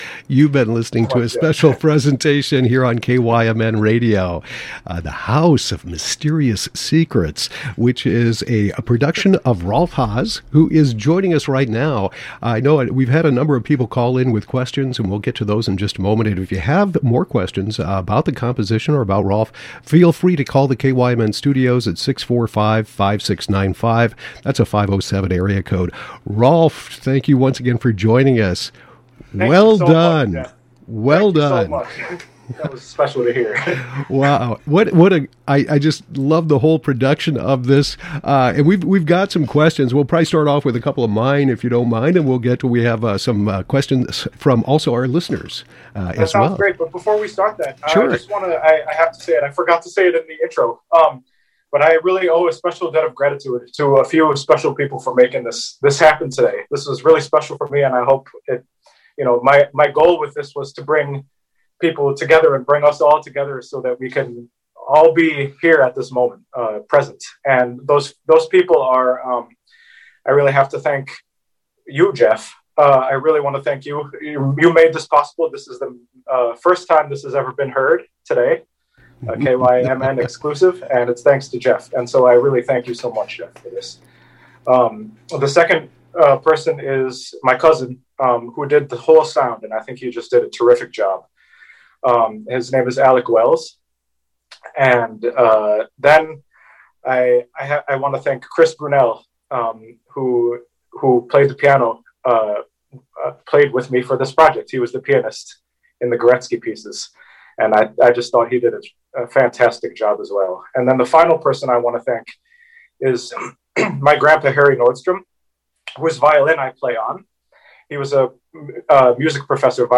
HoMS-post-interview-2.mp3